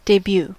Ääntäminen
IPA : /deby/